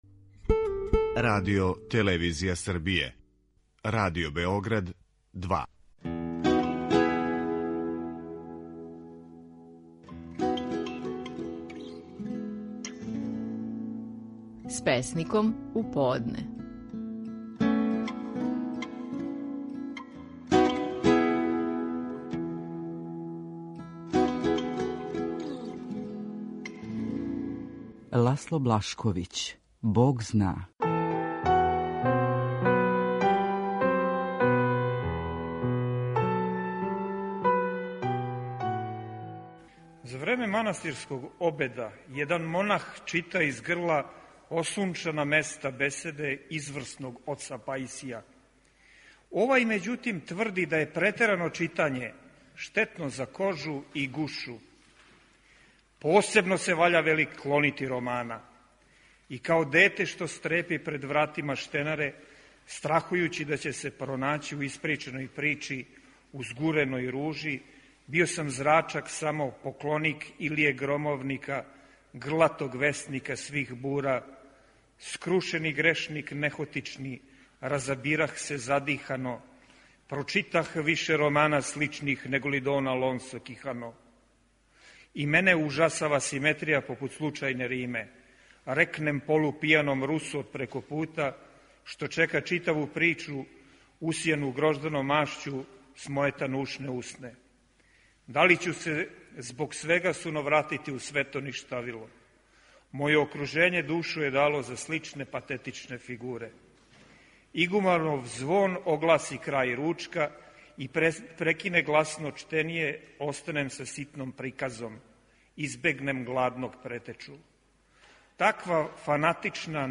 Стихови наших најпознатијих песника, у интерпретацији аутора.
Ласло Блашковић  говори песму „Бог зна".